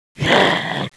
Index of /svencoop/sound/paranoia/zombie
zo_attack2.wav